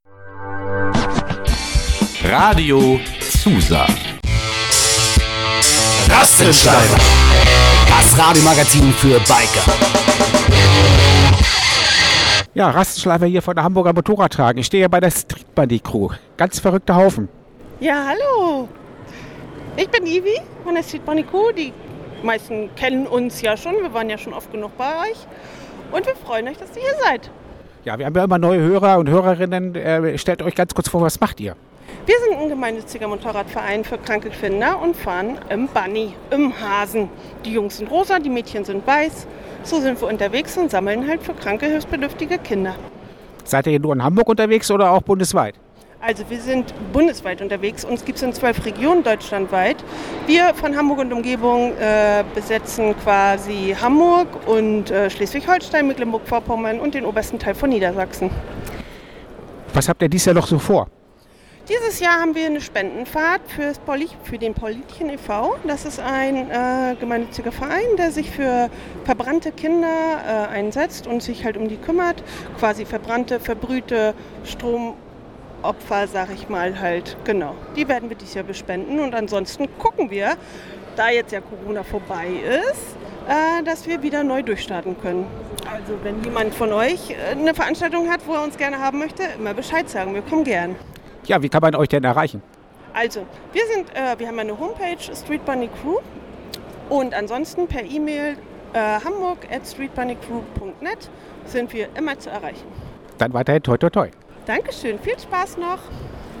Hamburg – Mitte Februar 2023 zogen die Hamburger Motorradtage (HMT) wieder tausende Motorradfahrer und Bikerinnen in die Messehallen.